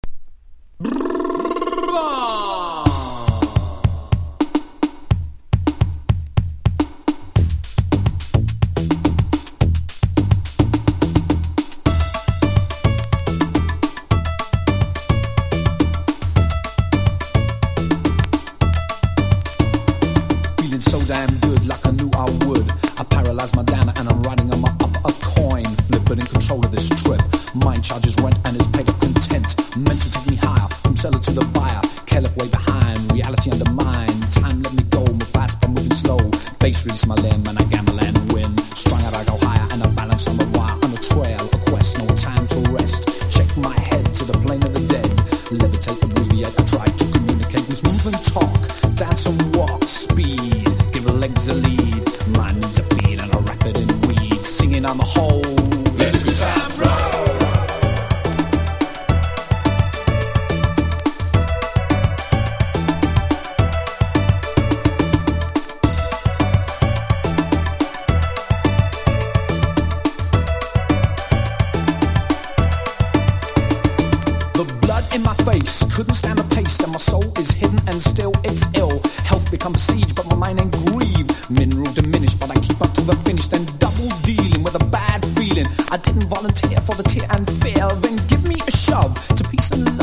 ACID JAZZ
コミカルなBEATが時代を象徴しています。